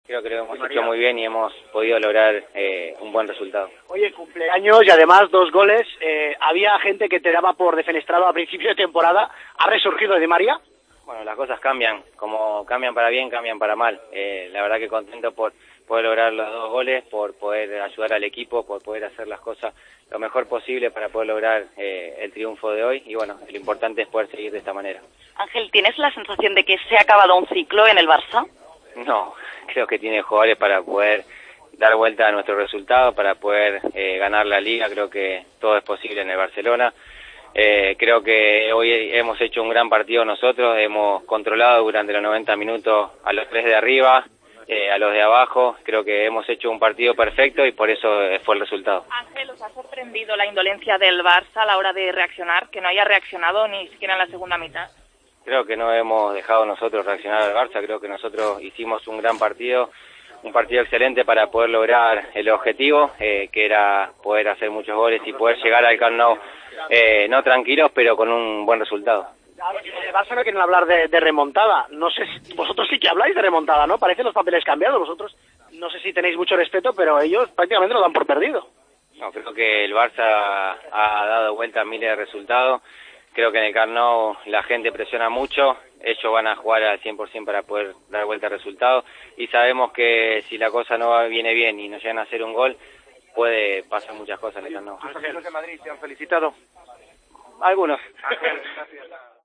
"Todo es posible en el Barcelona. Hemos hecho un gran partido, perfecto. No hemos dejado reaccionar al Barcelona. En el Camp Nou la gente presiona mucho. Algún jugador del Real Madrid me ha felicitado”, dijo el argentino del PSG, en zona mixta, tras la goleada ante el Barcelona.